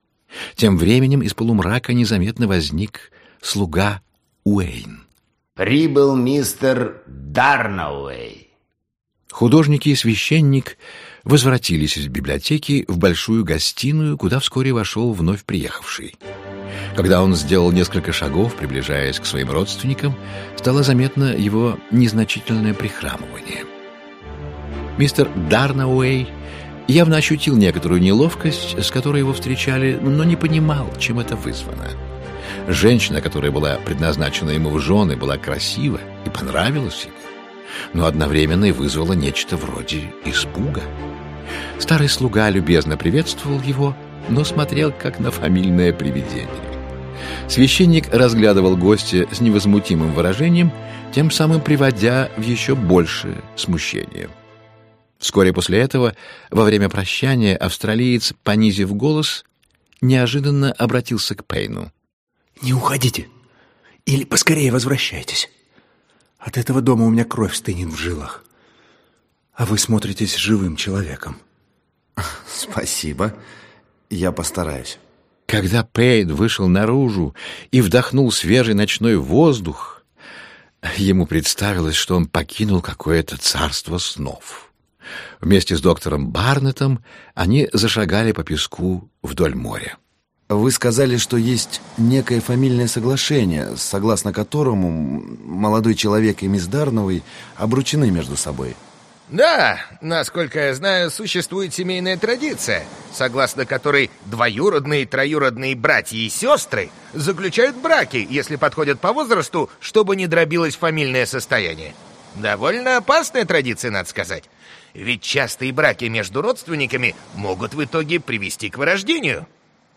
Аудиокнига Приключения отца Брауна (сборник 6 спектаклей) | Библиотека аудиокниг
Aудиокнига Приключения отца Брауна (сборник 6 спектаклей) Автор Гилберт Кит Честертон Читает аудиокнигу Виктор Раков.